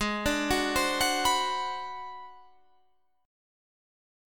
Listen to G#7#9b5 strummed